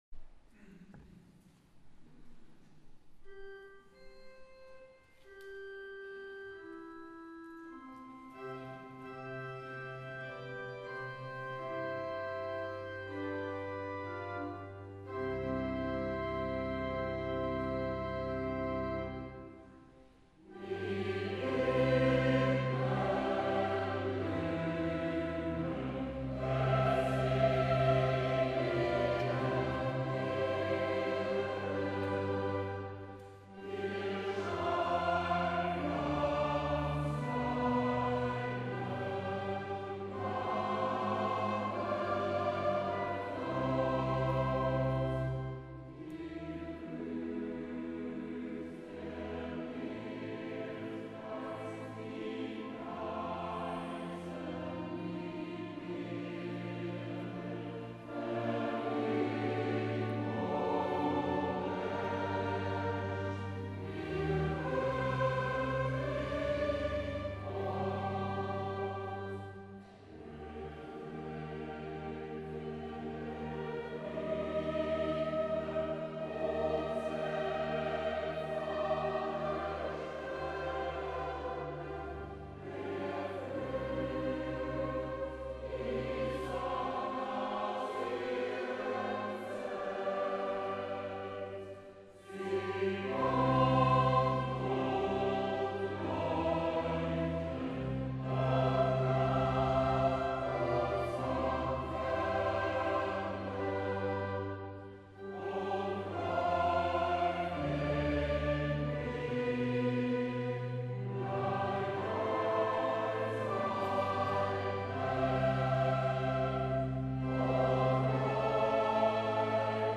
Bilder von der Festmesse am Sonntag 09. Oktober 2011 in der Stadtpfarrkirche
Blick auf Chor und Instrumentalisten